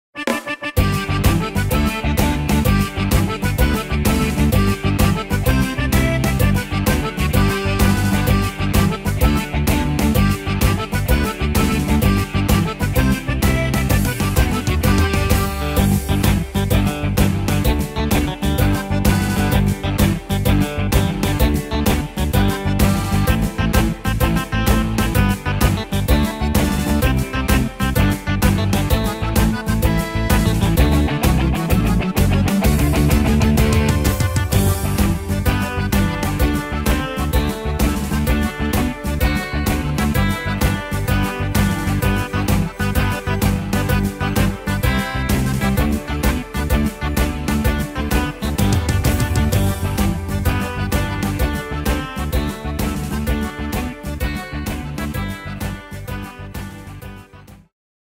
Tempo: 128 / Tonart: G-Dur